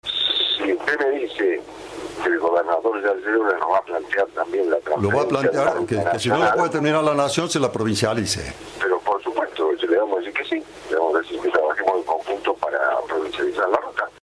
El jefe de Gabinete de Ministros, Guillermo Francos, confirmó en diálogo con Cadena 3 el traspaso de la ruta nacional 19 a la jurisdicción de la provincia de Córdoba.